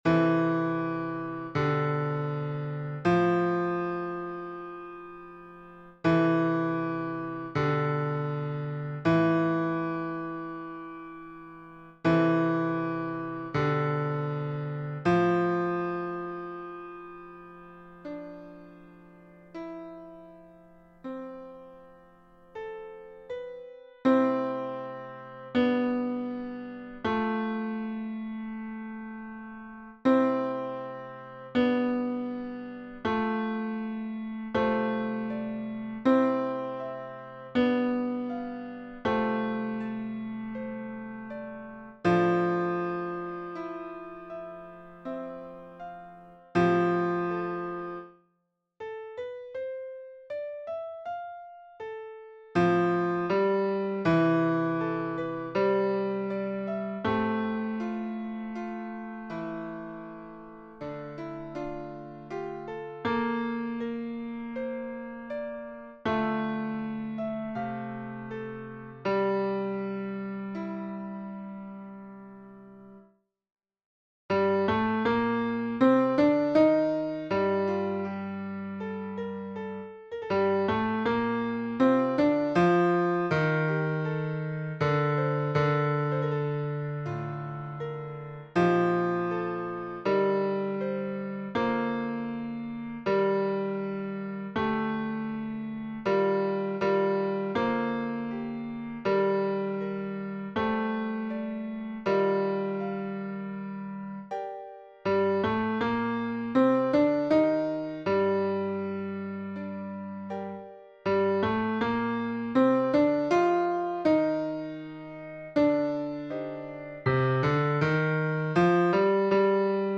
- Pièce pour chœur à 4 voix mixtes (SATB) + piano